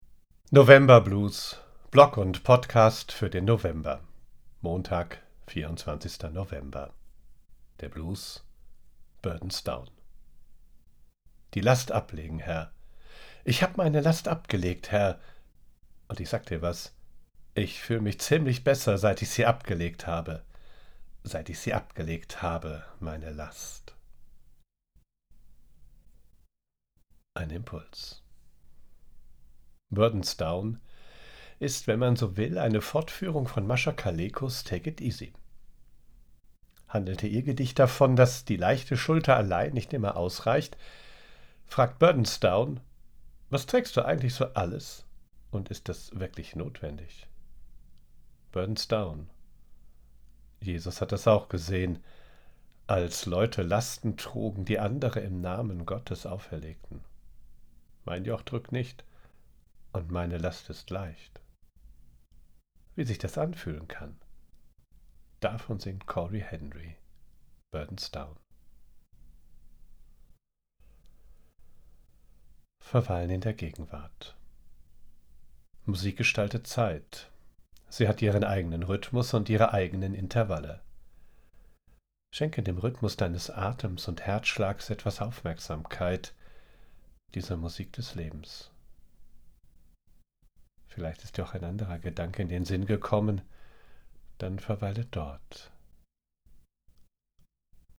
00:00:00 Der Blues: burdens down
Cory Henry: burdens down